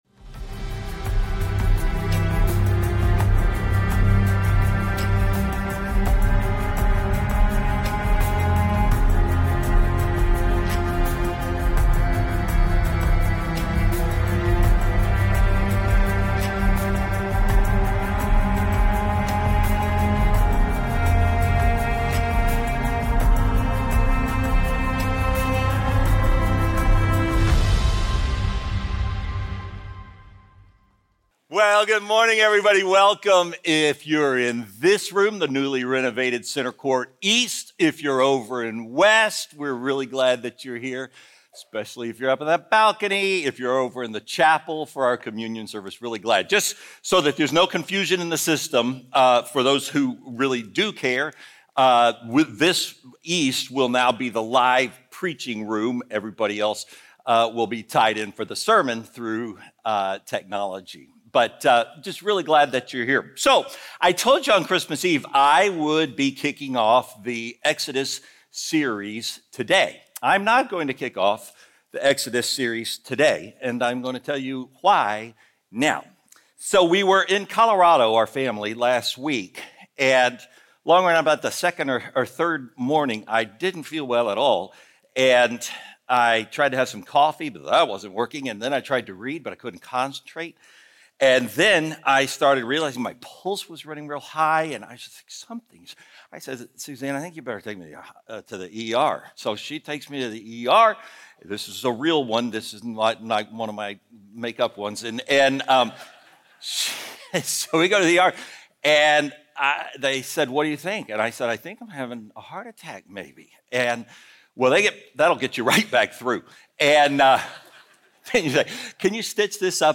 Faithbridge Sermons God Always Wins Jan 16 2025 | 00:51:28 1x 00:00 / 00:51:28 Subscribe Share Apple Podcasts Spotify Overcast RSS Feed Share Link Embed